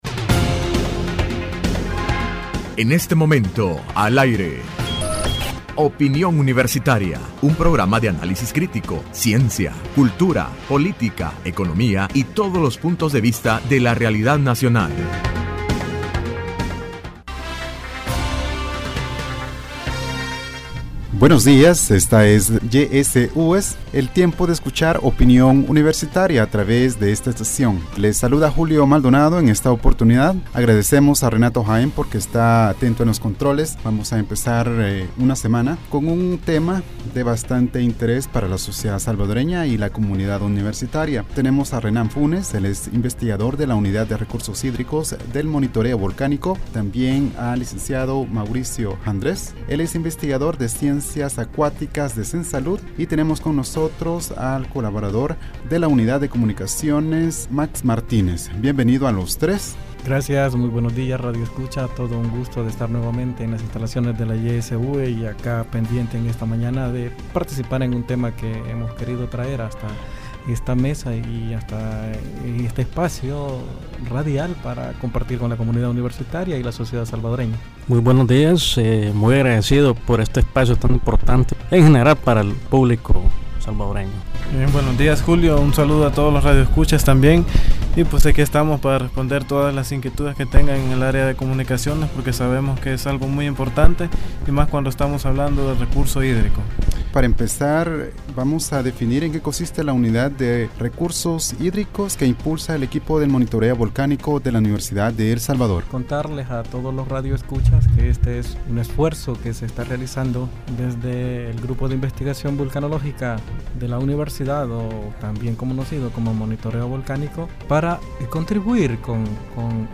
Entrevista Opinión Universitaria (25 de Julio 2016) : Unidad de Recursos Hídricos del equipo de Monitoreo Volcánico de la UES.